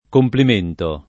[ komplim % nto ]